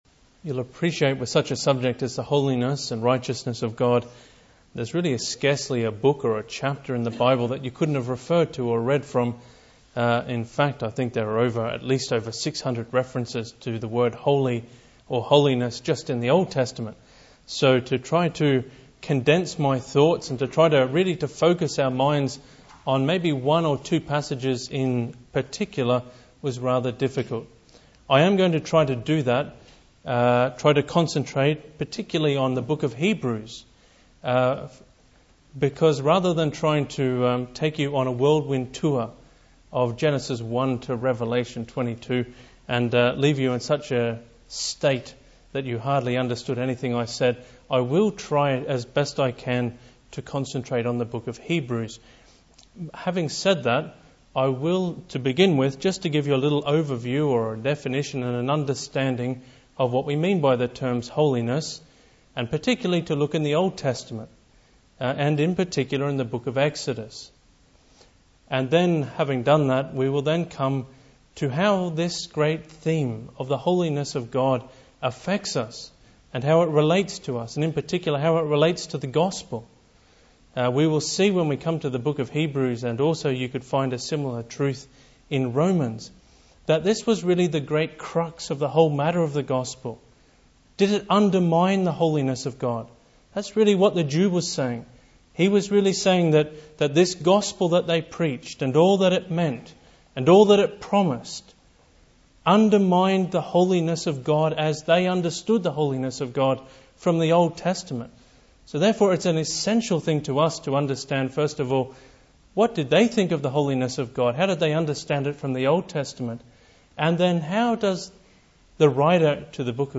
He defines his subject and traces how God has revealed His holiness to His people & the world (Message preached 14th Jan 2010)